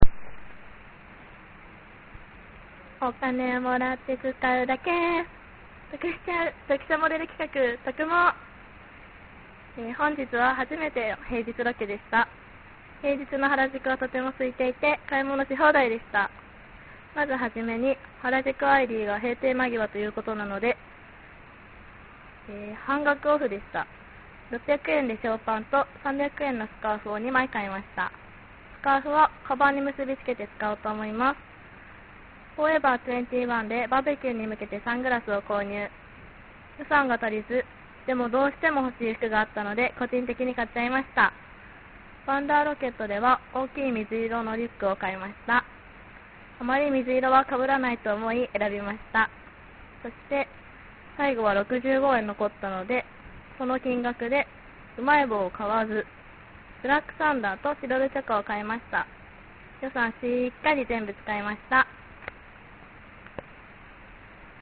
リポート等の音声はスタジオ録音ではなく現地録りになりますので風の音や他の人の声などの雑音が入っていたりしますがご了承願います。